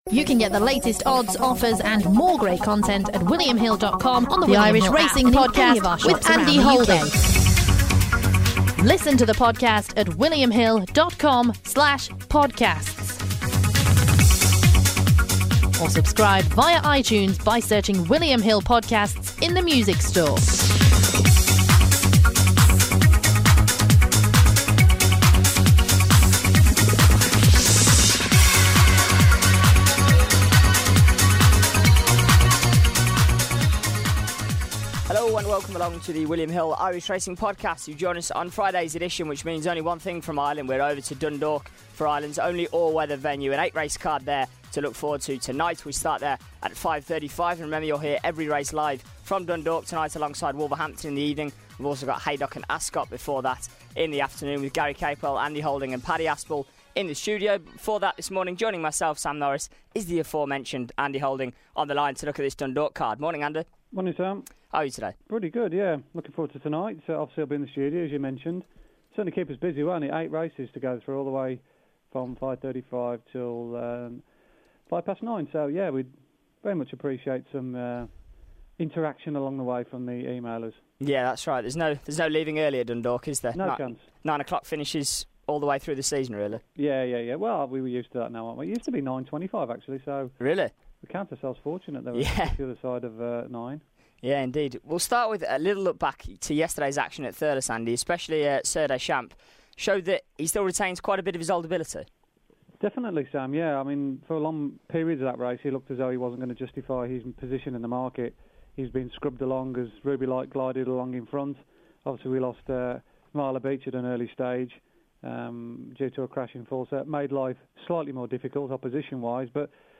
on the line to offer up his thoughts on every race on tonight's card.